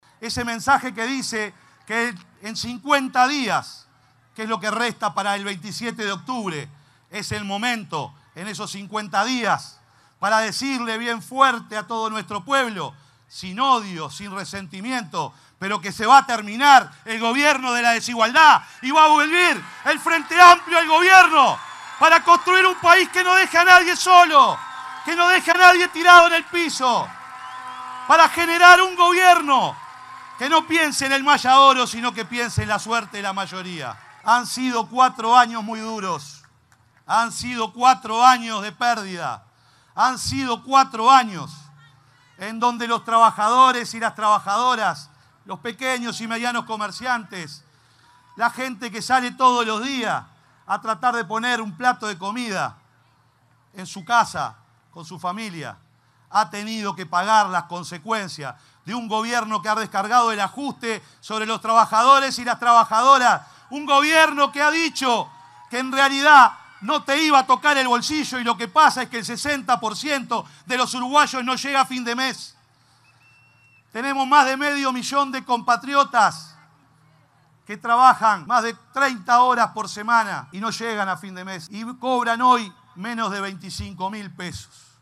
La lista 609 del Frente Amplio, encabezada por el senador Alejandro Sánchez, lanzó su campaña de cara a las próximas elecciones nacionales de octubre, y también marcó la primera presencia de la candidata al Senado Blanca Rodríguez en un acto de militancia, luego de su presentación en la Huella de Seregni.